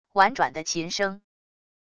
婉转的琴声wav音频